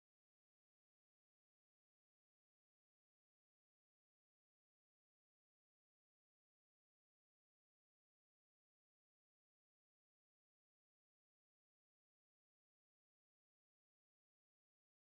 pixel pebble get out yell sound effects free download